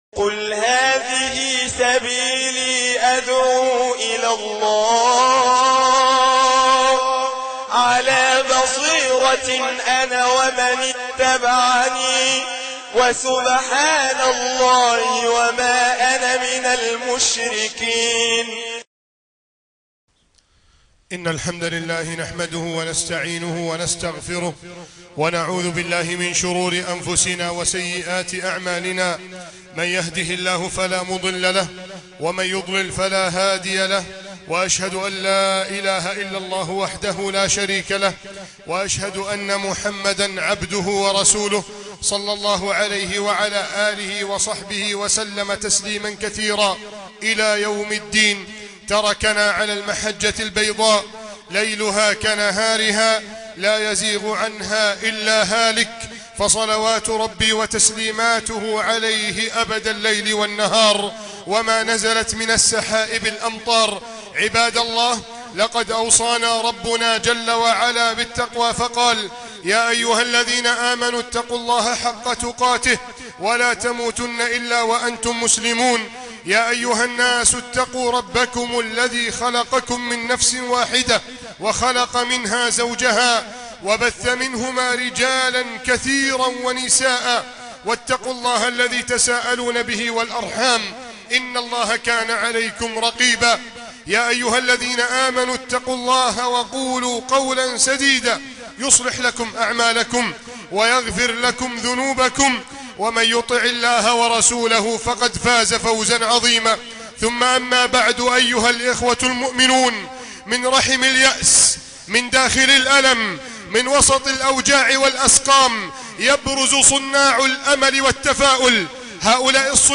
خطبة الجمعة ) مسجد التابعين - بنها